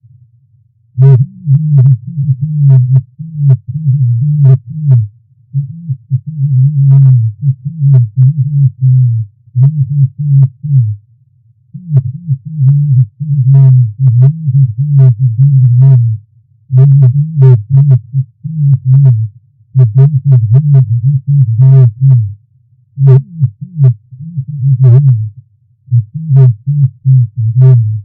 A Whale Recites Poetry